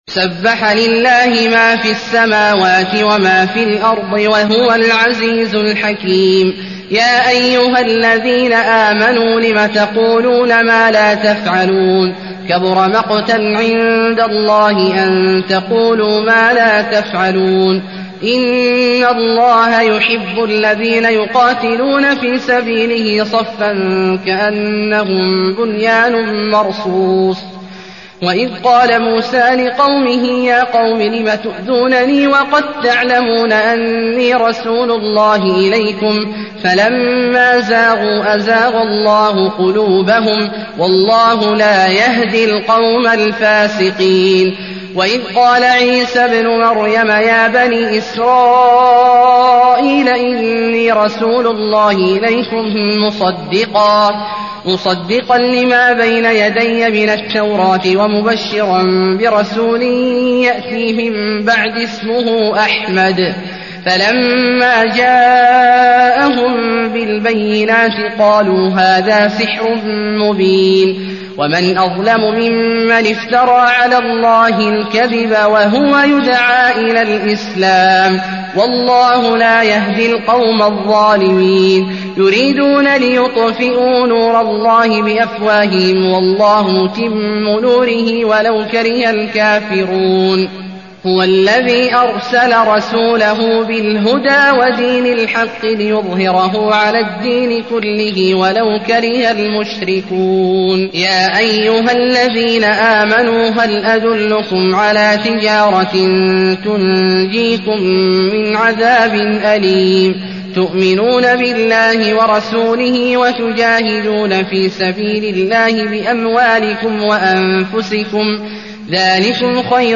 المكان: المسجد النبوي الصف